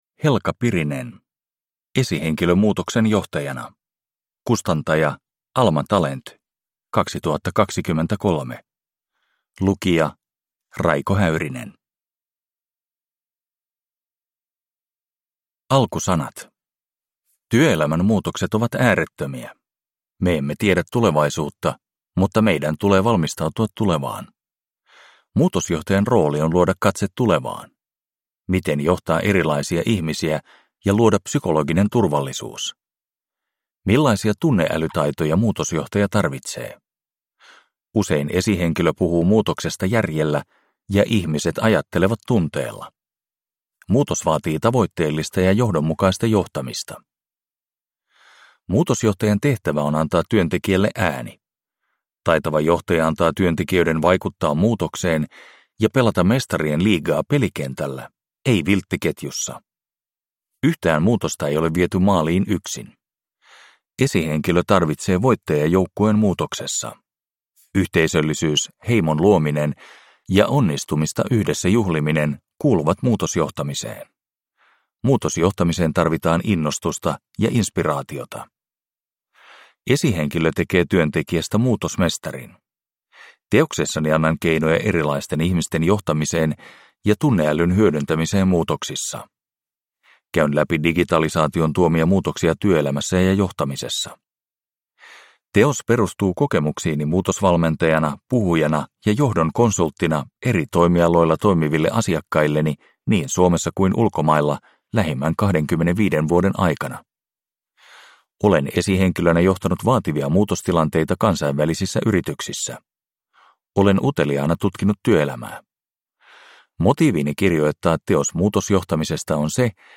Esihenkilö muutoksen johtajana – Ljudbok – Laddas ner